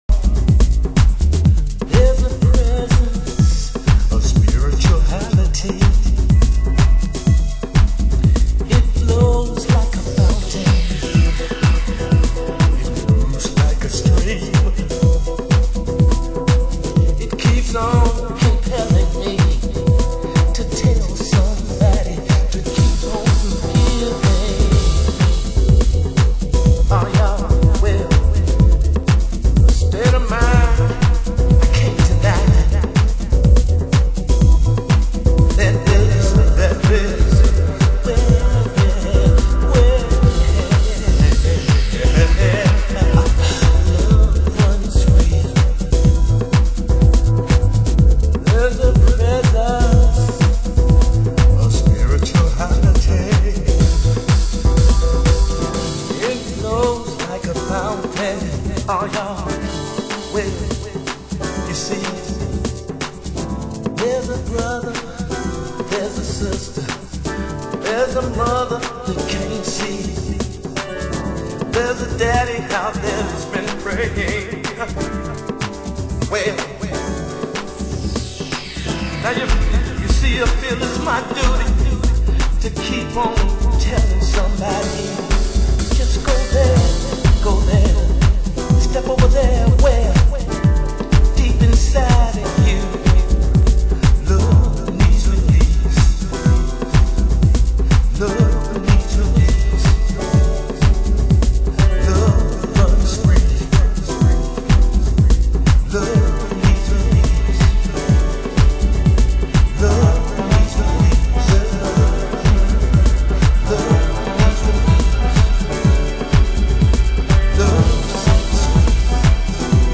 HOUSE MUSIC
盤質：B面に点在する傷(試聴箇所になっています)/チリパチノイズ有　　ジャケ：シール剥がれ、取り出し口1.5センチ破れ